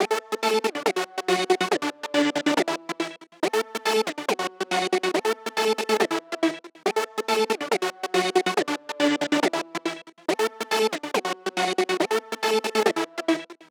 Index of /archive/TRUTH-HZ/2023_TRUTH-HZ_USB/Sample Packs/Crowdsourced Sample Pack/Drum Kit/808s & Subs